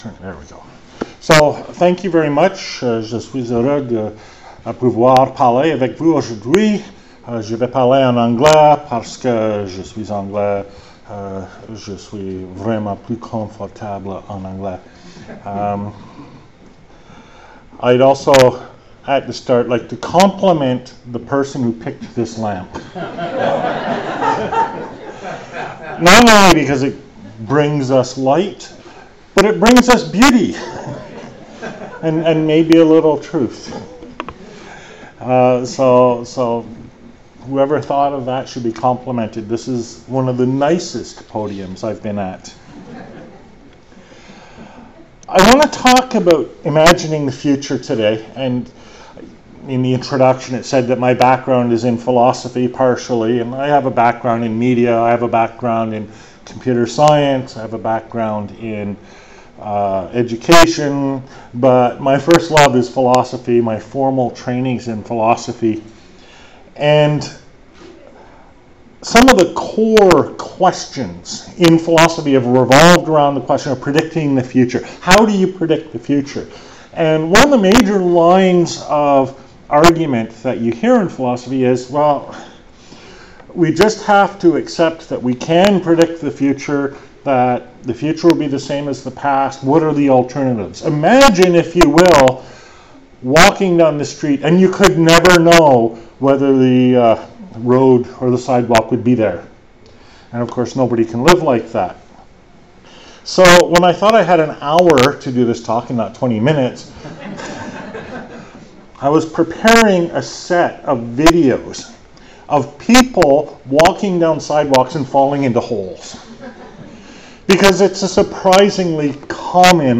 Talk I gave at SSHRC's Fall Forum called Imaging Canada's Future.